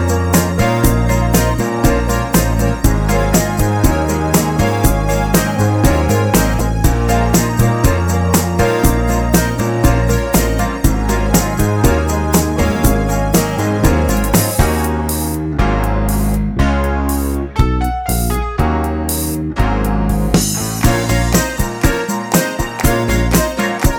No Harmonica with Backing Vocals Pop (1970s) 3:21 Buy £1.50